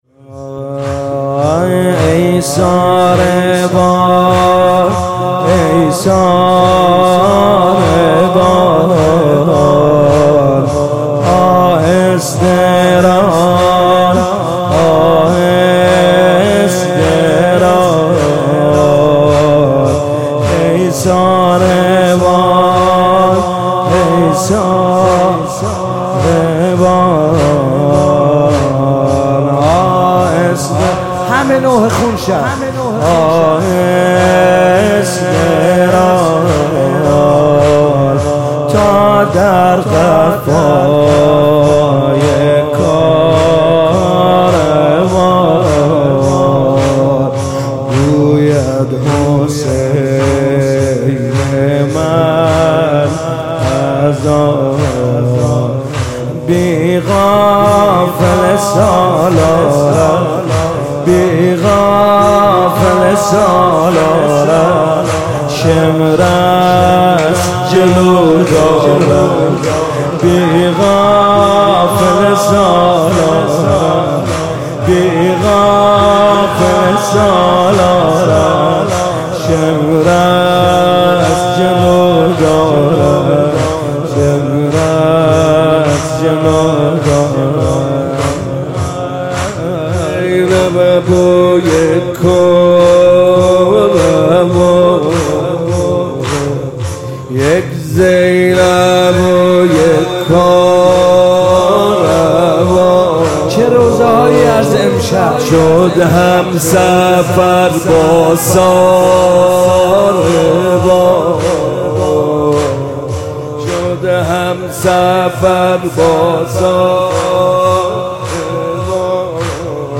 مداحی جدید کربلایی حسین طاهری شب یازدهم محرم۹۸ هیات مکتب الزهرا (س) تهران سه شنبه 19 شهریور ۱۳۹۸